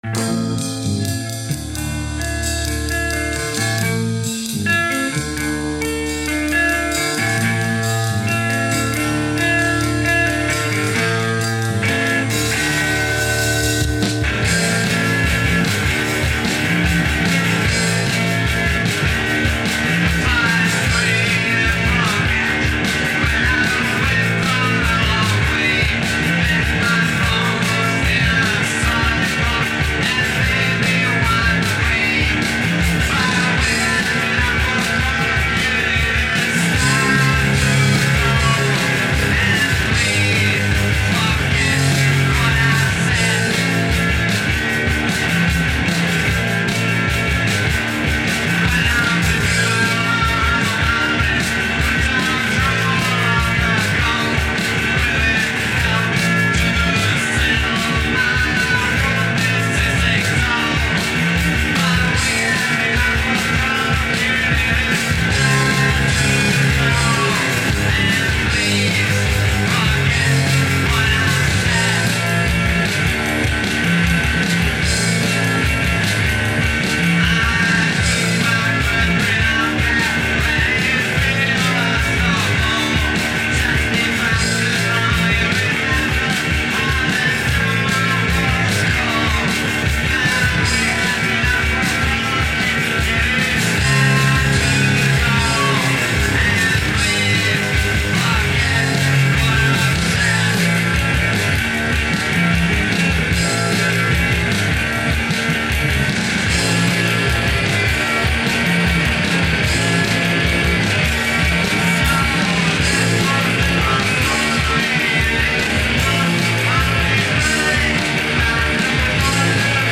guitar and voice
drums
bass and voice
with music more trashy/punky and art influenced.